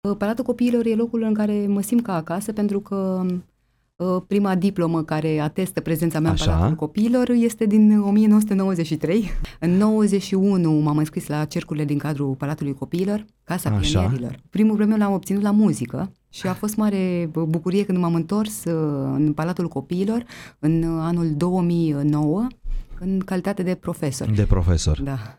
Invitată în emisiunea „Față în față”, la Viva FM, aceasta a vorbit despre planurile pe care le are pentru perioada următoare și despre legătura specială pe care o are cu acest loc.